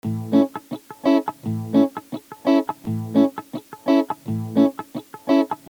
پکیج ریتم و استرام گیتار الکتریک ایرانی
دموی صوتی ریتم شیش و هشت بندرعباسی :